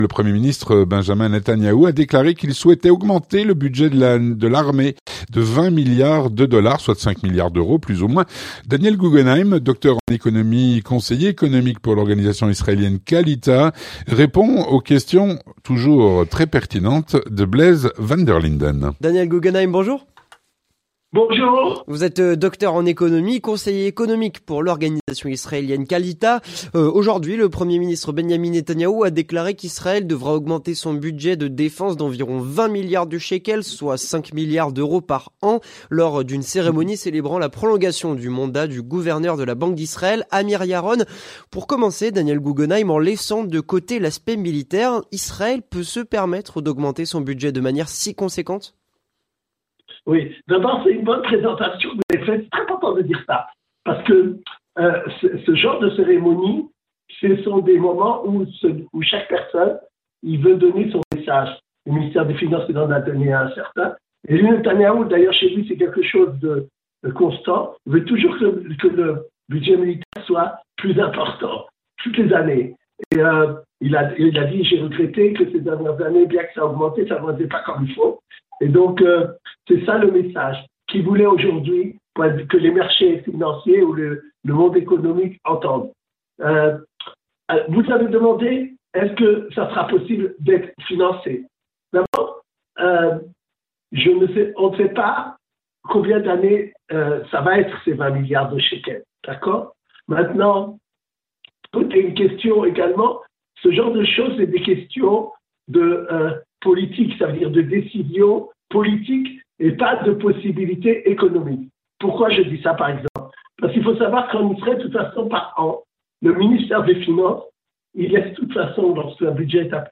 L'entretien du 18H - Israël veut augmenter son budget militaire de 5 milliards d'euros.